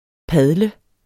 Udtale [ ˈpaðlə ]